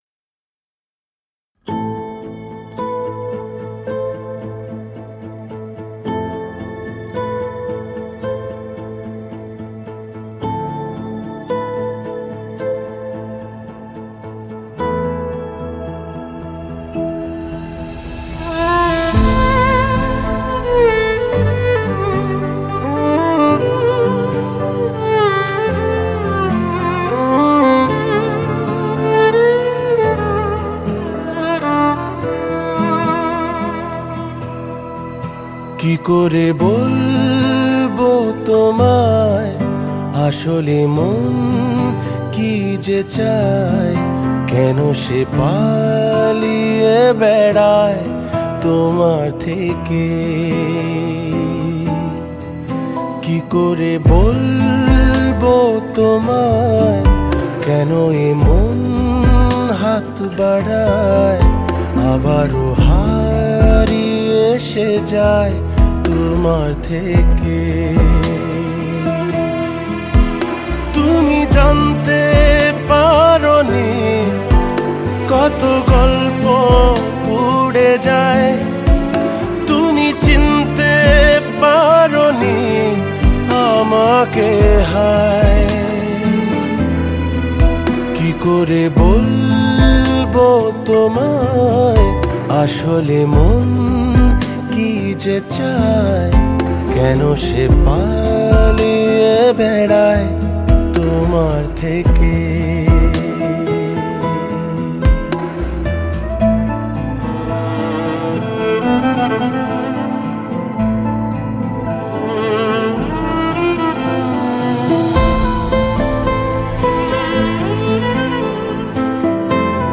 Home » Bengali Mp3 Songs » 64Kbps